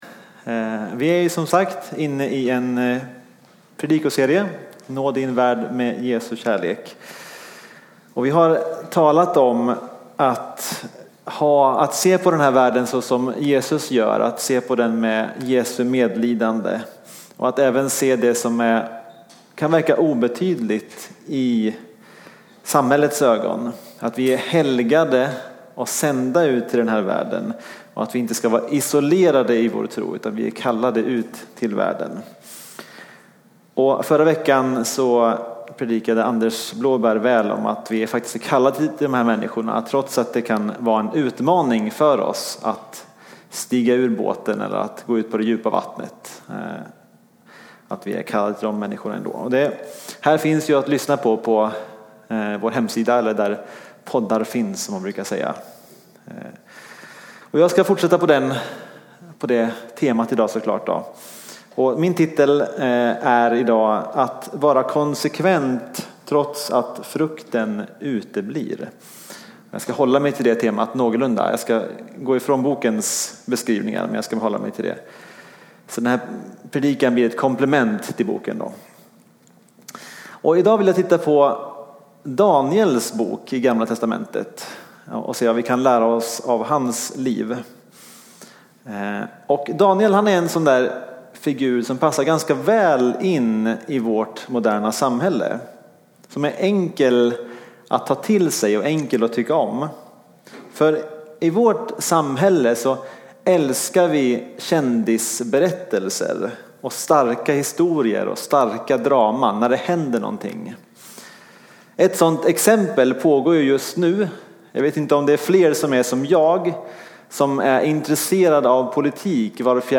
Predikan